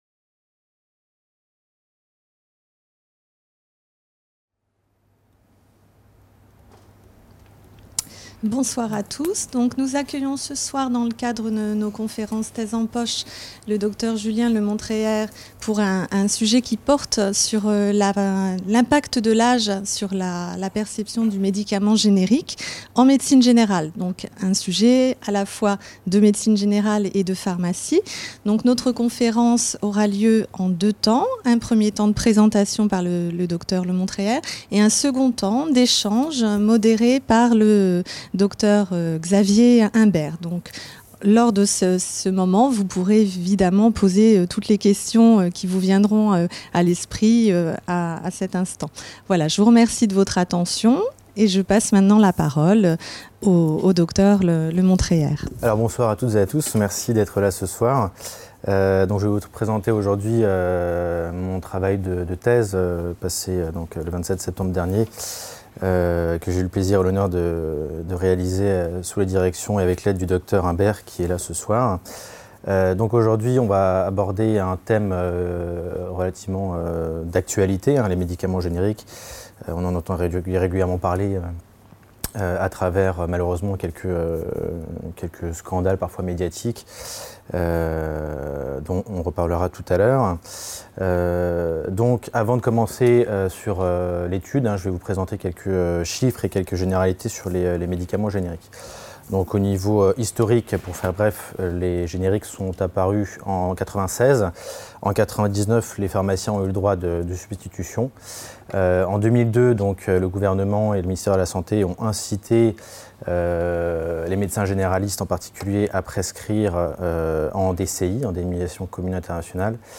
Les conférences santé de la BU La quatrième conférence du cycle « Thèse en poche » 2017-2018 organisé à la Bibliothèque universitaire Madeleine-Brès propose de s’interroger sur la perception par le patient âgé de la prescription d’un médicament générique. Un climat de défiance persiste autour des génériques, considérés comme bas de gamme notamment chez les patients les plus âgés. L’information délivrée aux patients notamment par le pharmacien ou le médecin généraliste, permet d’améliorer l’acceptation et la confiance envers les génériques.